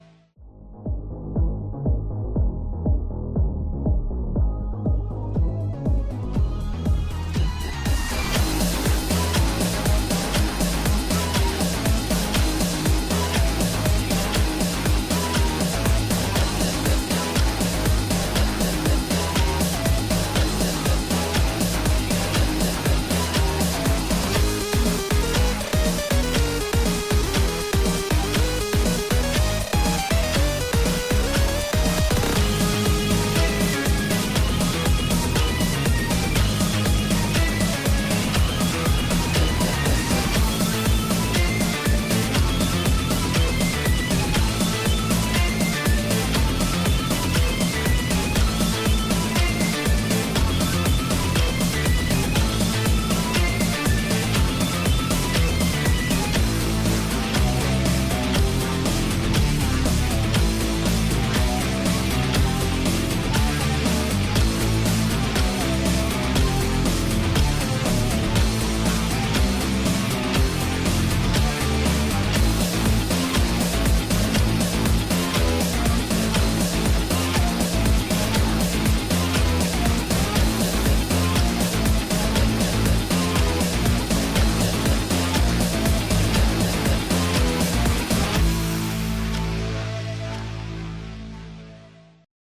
(без слов)